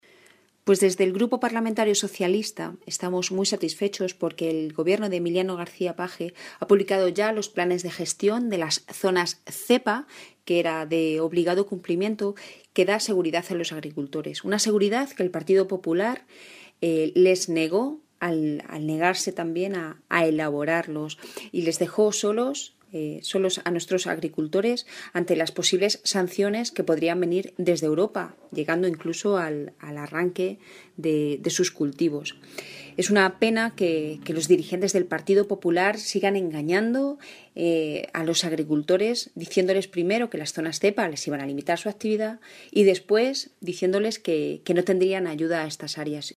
La diputada del Grupo Parlamentario Socialista en las Cortes de Castilla-La Mancha, Rosario García, ha mostrado su satisfacción por el presupuesto que este año la consejería de Agricultura va a destinar para los cultivos herbáceos en las zonas ZEPA.
Cortes de audio de la rueda de prensa